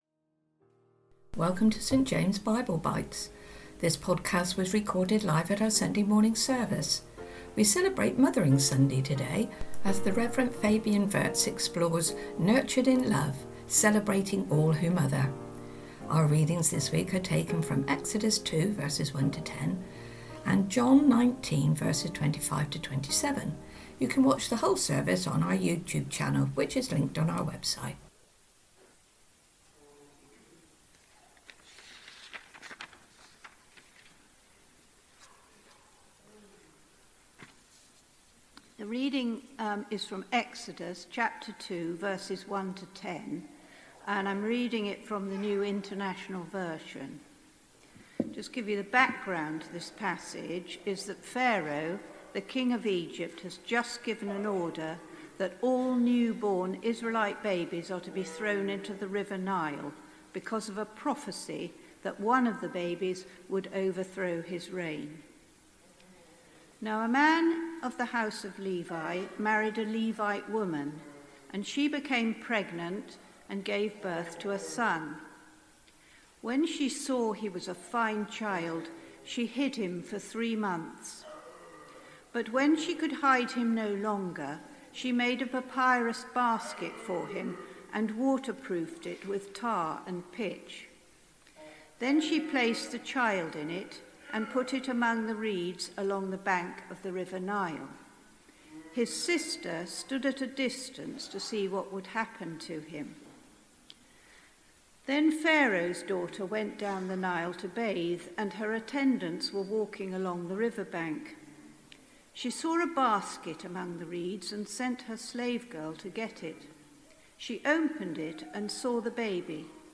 Sunday Talks